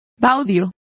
Complete with pronunciation of the translation of baud.